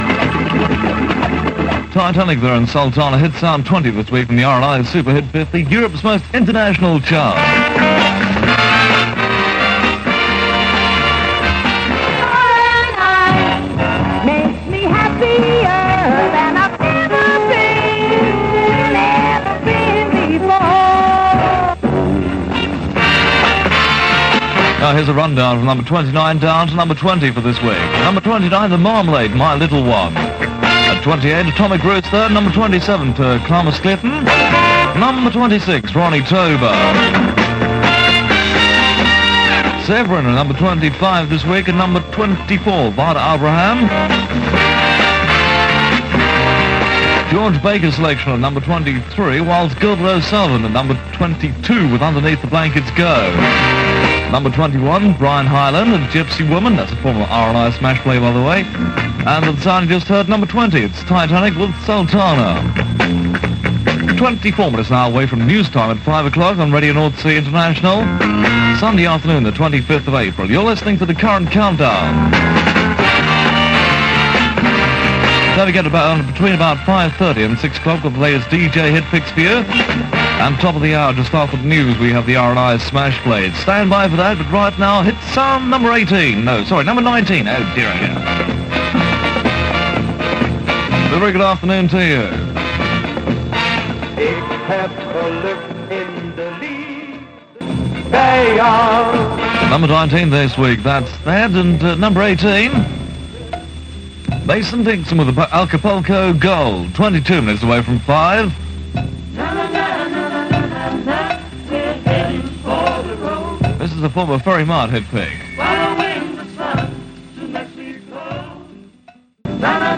from the ‘Super Hit 50’ show on Radio Northsea International, 25th April 1971